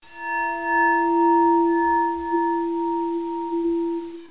BOWLBELLOOPLOWER.mp3